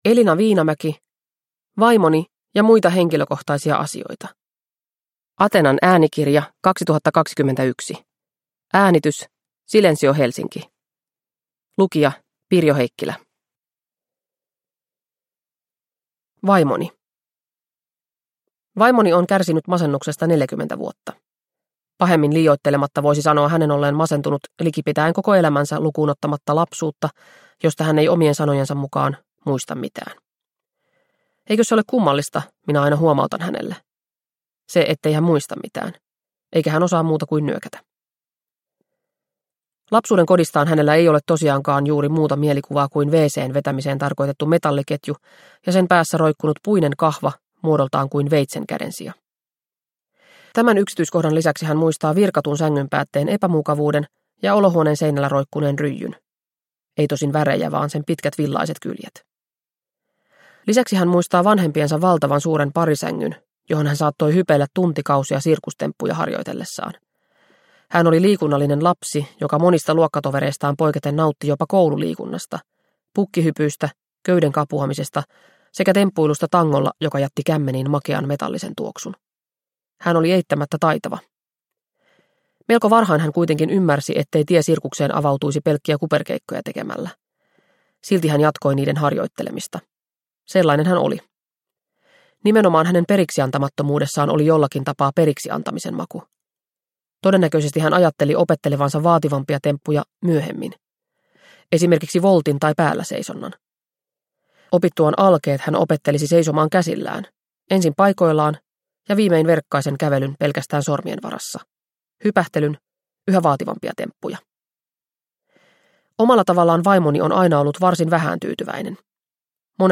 Vaimoni – Ljudbok